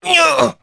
Oddy-Vox_Damage_jp_02.wav